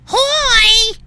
Effect Download hOI! Effect Sound Download Download for iphone